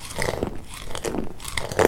default_eat.ogg